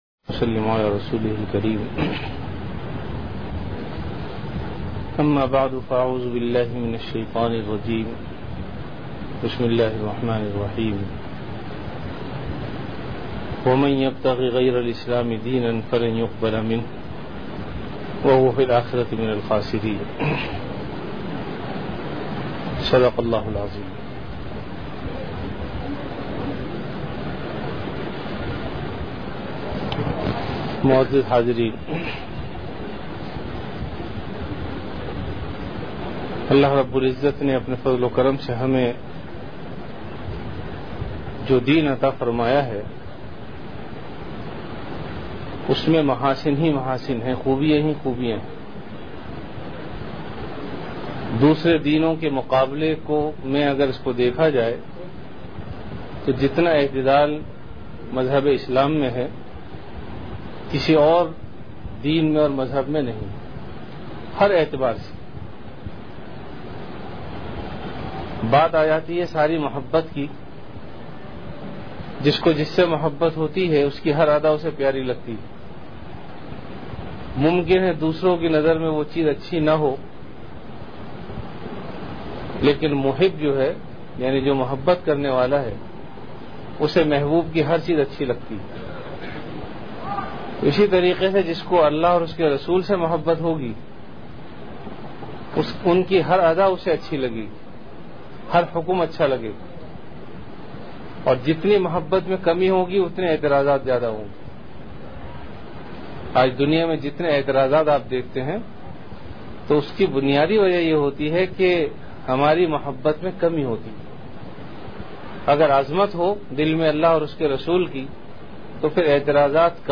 Bayanat
After Isha Prayer